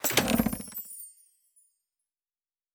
Prize Chest (3).wav